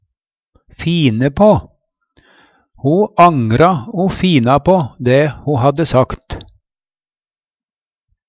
DIALEKTORD PÅ NORMERT NORSK fine på gjera om Eksempel på bruk Ho angra o fina på dæ ho hadde sagt.